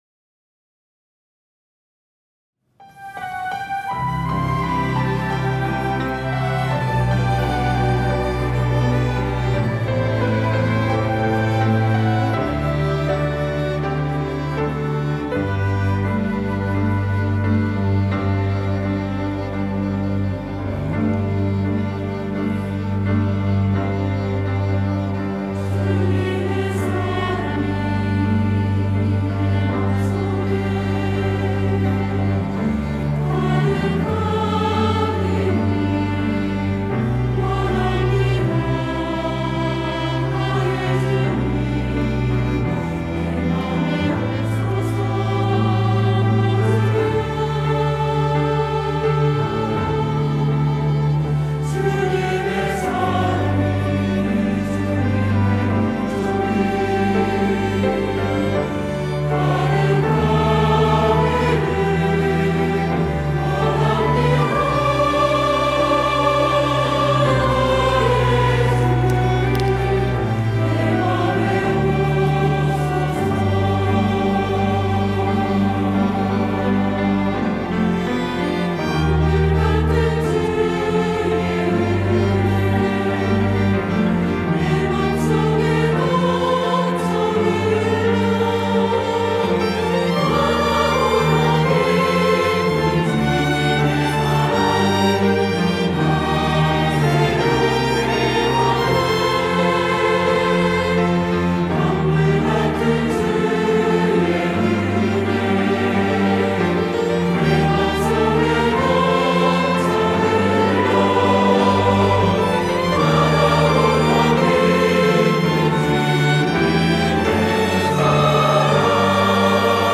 호산나(주일3부) - 주님의 사랑
찬양대